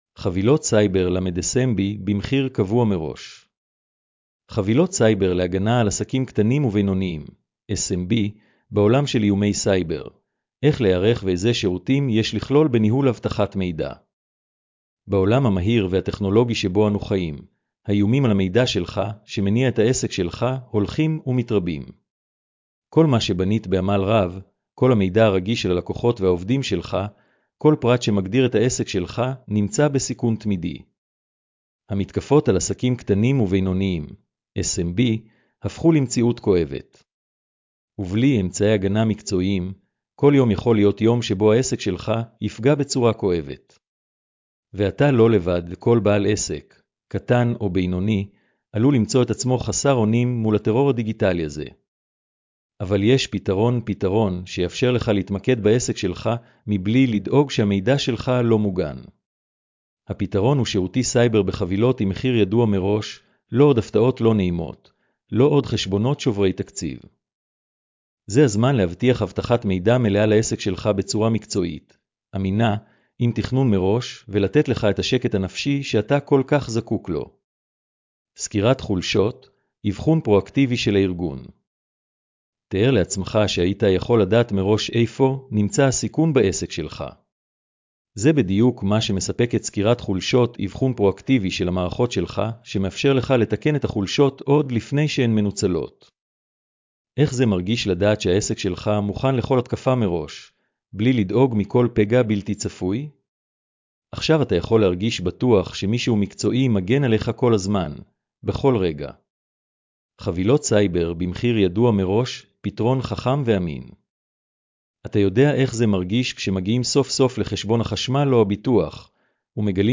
השמעת המאמר לכבדי ראייה